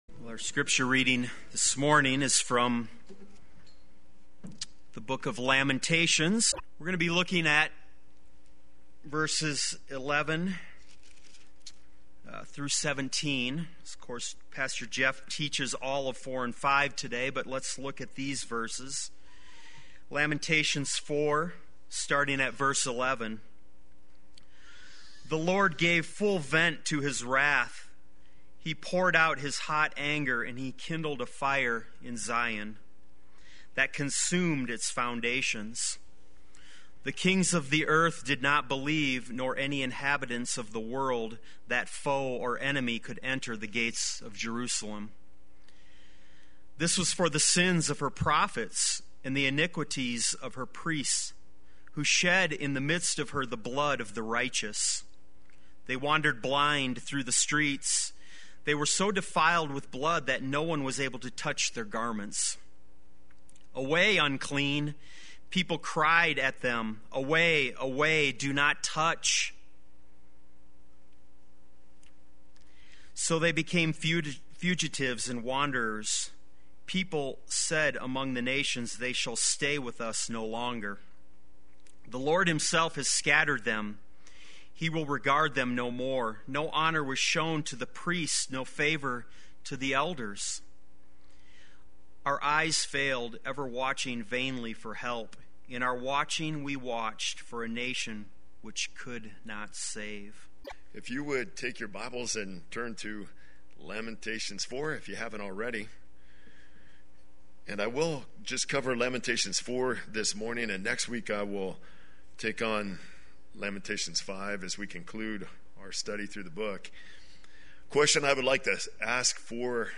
Play Sermon Get HCF Teaching Automatically.
Purpose in Darkness Sunday Worship